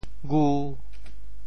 潮语发音
gu5.mp3